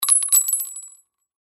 Звук упавшей гильзы от пистолета использованный патрон